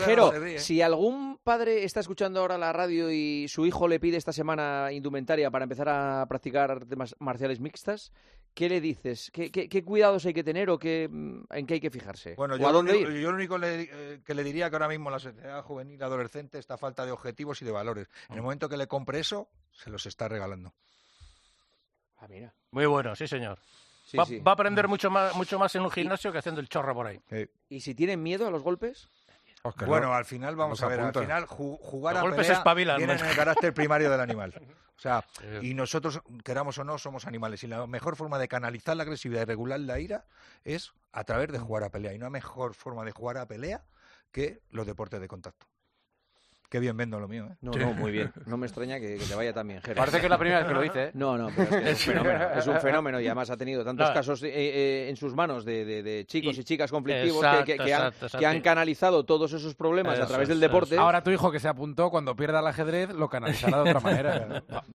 "Pero si algún padre está escuchando ahora la radio y su hijo le pide esta semana indumentaria para empezar a practicar artes marciales mixtas, ¿qué le dices? ¿Qué cuidados hay que tener? ¿En qué hay que fijarse? ¿A dónde ir?", le preguntaba Juanma Castaño a Jero García en El Partidazo de COPE.